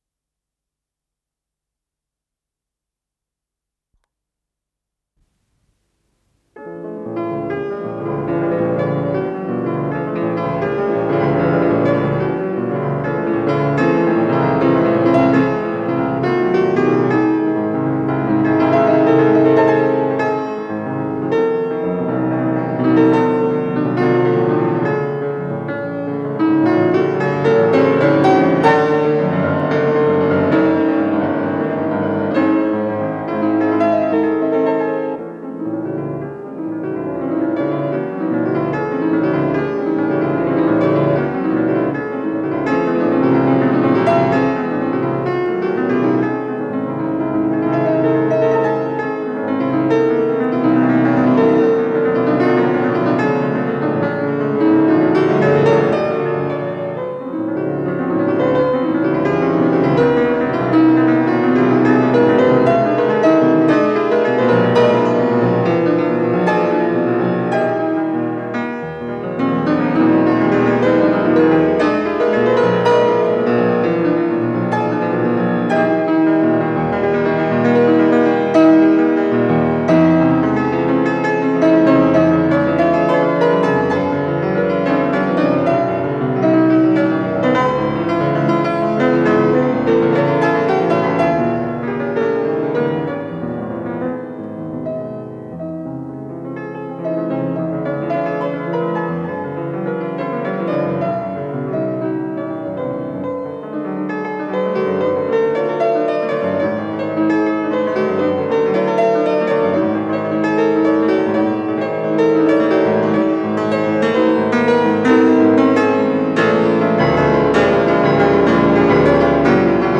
с профессиональной магнитной ленты
ПодзаголовокДо диез минор
ИсполнителиТатьяна Николаева - фортепиано
ВариантДубль моно